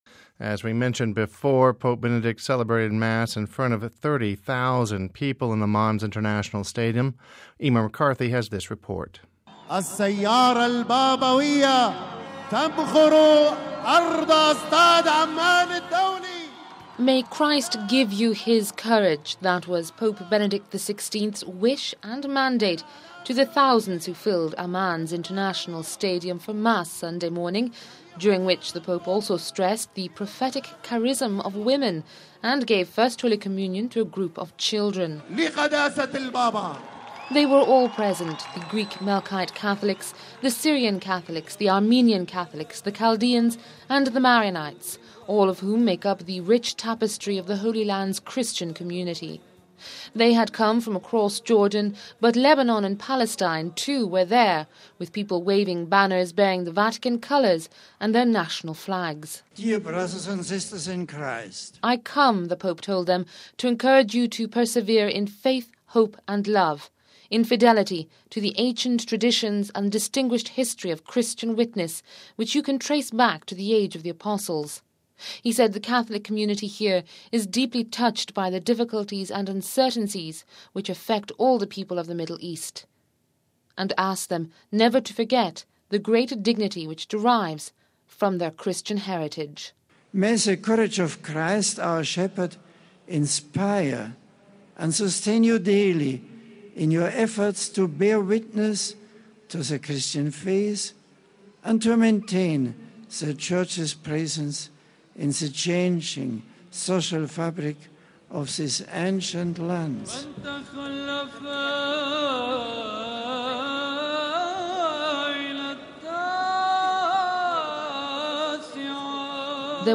Home Archivio 2009-05-10 18:38:14 Pope Celebrates Mass in Amman (10 May 09 - RV) Pope Benedict on Sunday celebrated Mass at Amman's International Stadium. We have this report...